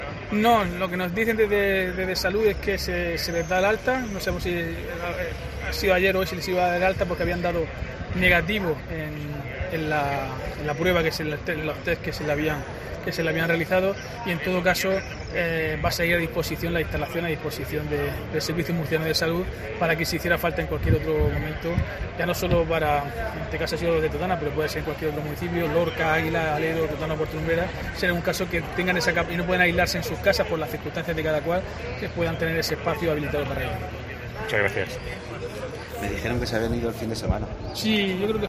Diego José Mateos, alcalde de Lorca sobre negativo CAR